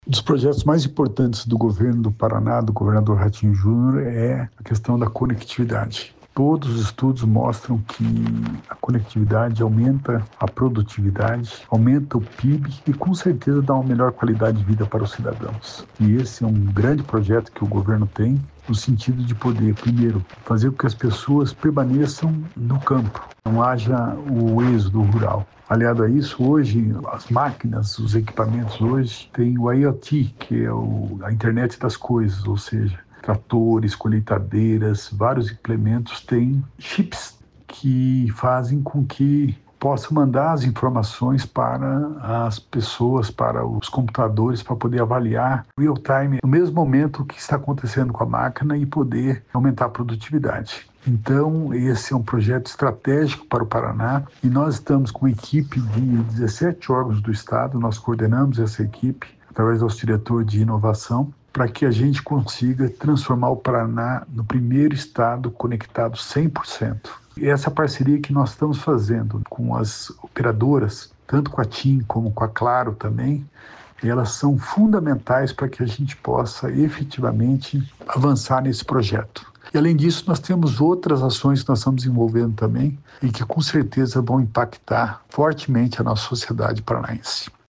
Sonora do secretário da Inovação, Modernização e Transformação Digital, Alex Canziani, sobre a instalação de novas torres em áreas rurais no Paraná | Governo do Estado do Paraná